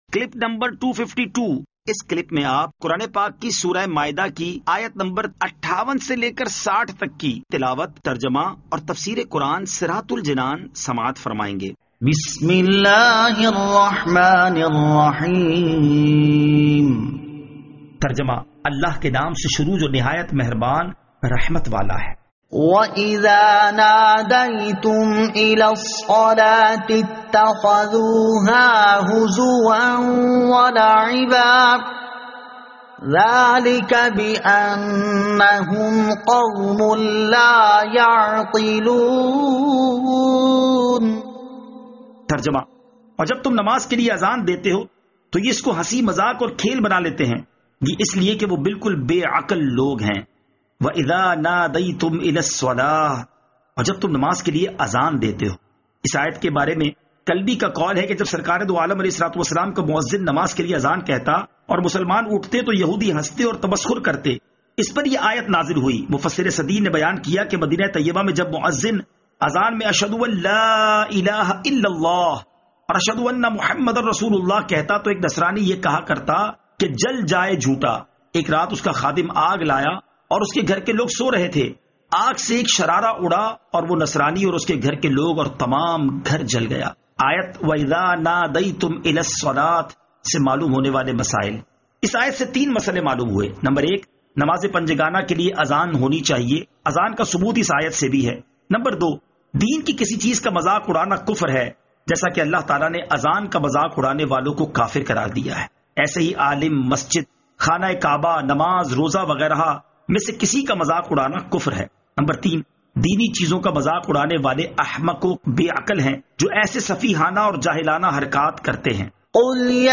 Surah Al-Maidah Ayat 58 To 60 Tilawat , Tarjama , Tafseer